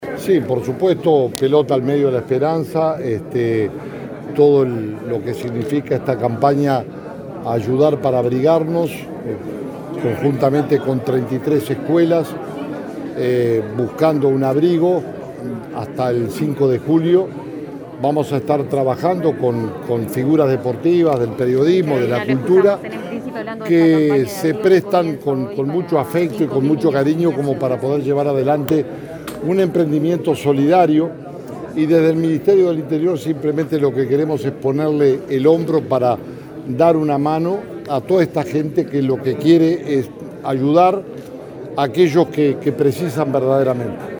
“La contribución de solidaridad es importante para dar una mano a quienes lo necesitan y hace al diferencial del país”, dijo el ministro Jorge Larrañaga en el lanzamiento de la campaña “Juntos ayudemos a abrigar”, que se desarrolla del 22 de junio al 5 de julio y cuyo objetivo es recolectar camperas, buzos, frazadas, calzado y alimentos no perecederos para entregarlos a más de 5.000 niños de 33 escuelas del proyecto Moña.